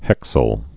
(hĕksəl)